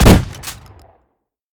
pump-shot-9.ogg